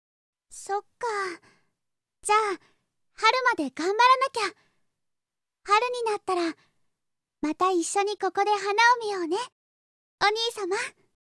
・生まれつき病弱で、ほとんど外に出た事がない
【サンプルボイス】